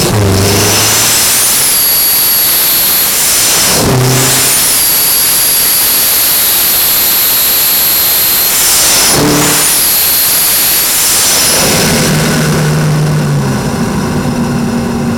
Turbo sound
turbo.wav